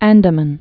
(ăndə-mən)